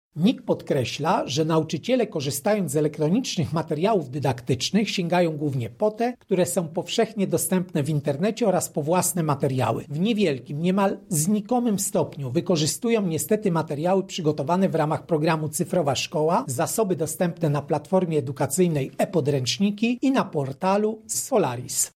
Problemem jest także nieużywanie przez nauczycieli oraz uczniów elektronicznych pomocy naukowych – mówi Krzysztof Kwiatkowski, prezes Najwyższej Izby Kontroli: